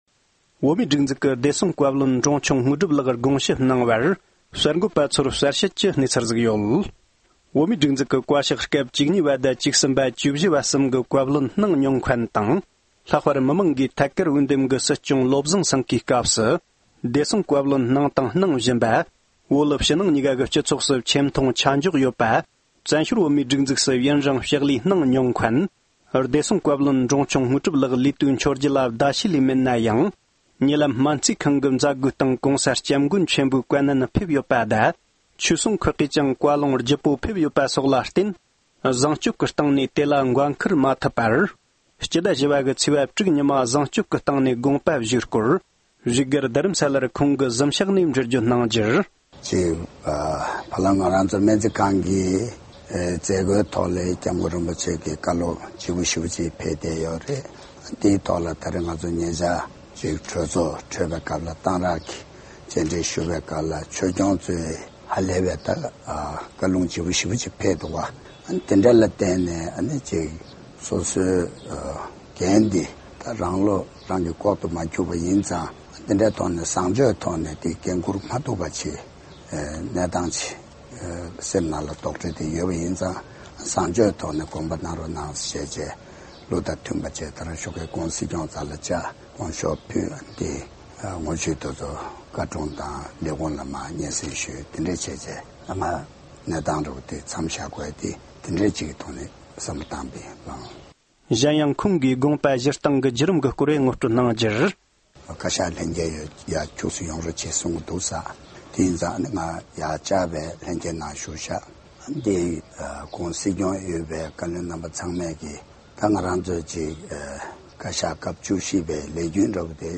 སྒྲ་ལྡན་གསར་འགྱུར།
བདེ་སྲུང་བཀའ་བློན་འབྲོང་ཆུང་དངོས་གྲུབ་མཆོག་གིས་རྩ་དགོངས་ཞུས་རྗེས་གསར་འགོད་པའི་དྲི་བར་ལན་འདེབས་གནང་བ། ༢༠༡༦།༤།༦